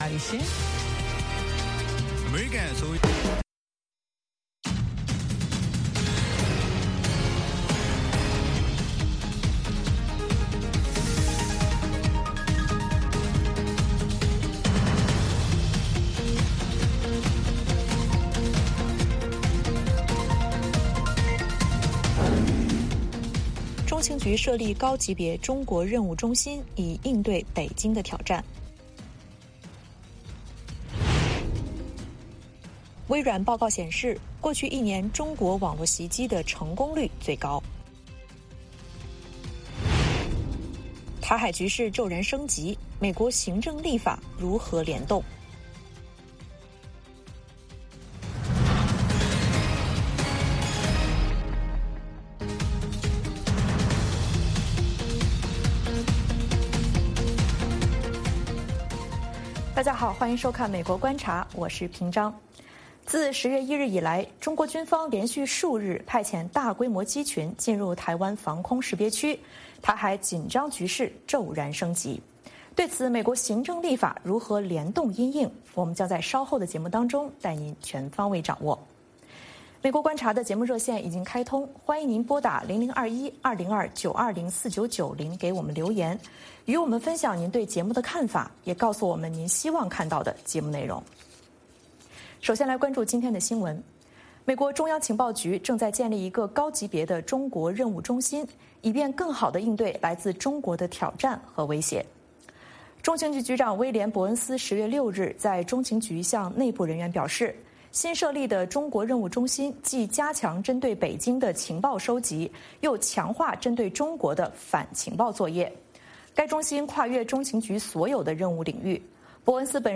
北京时间早上6点广播节目，电视、广播同步播出VOA卫视美国观察。 “VOA卫视 美国观察”掌握美国最重要的消息，深入解读美国选举，政治，经济，外交，人文，美中关系等全方位话题。节目邀请重量级嘉宾参与讨论。